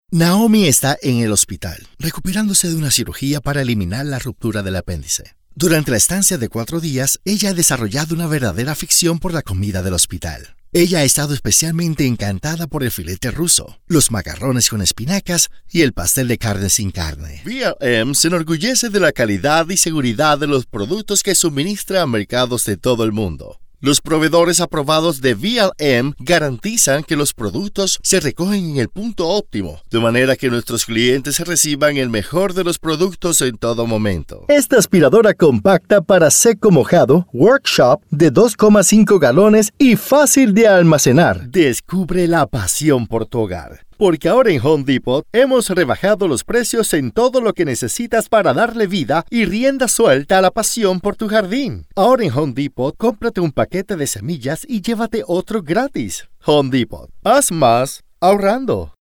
A warm and deep native Latin American Spanish voice ideal for narrations, sexy and fun for commercials, smooth and professional for presentations.
Sprechprobe: Industrie (Muttersprache):